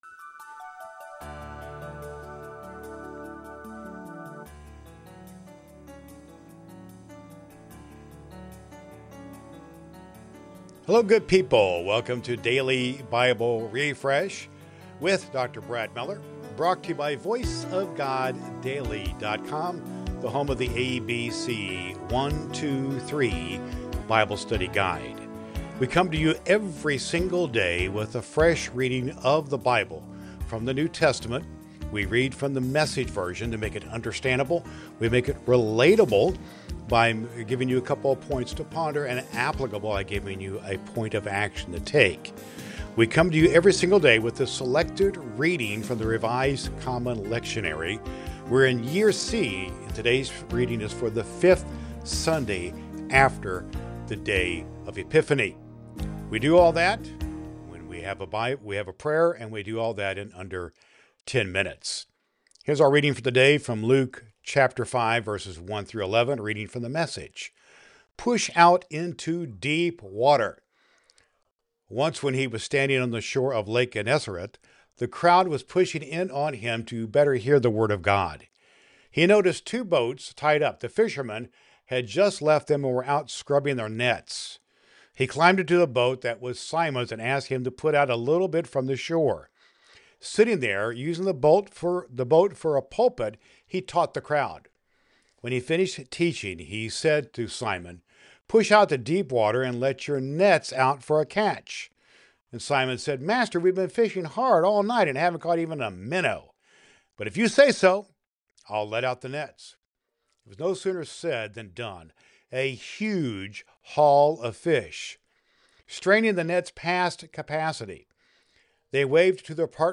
• Understandable: A reading from the New Testament (usually the Gospel) selected from the Revised Common Lectionary using "The Message" translation.
• A prayer for your day.